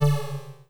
Hit & Impact
Hit2.wav